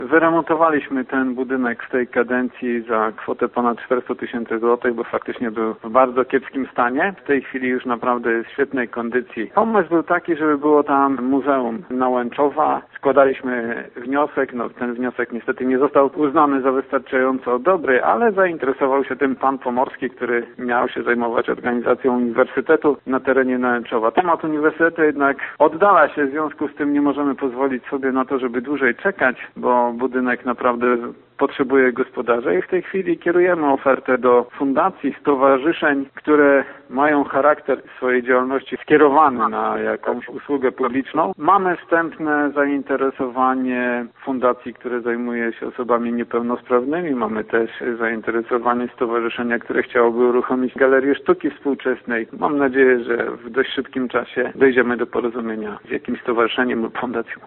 Jeszcze kilka lat temu stan techniczny „Ochronki” pozostawiał wiele do życzenia – przypomina burmistrz Andrzej Ćwiek: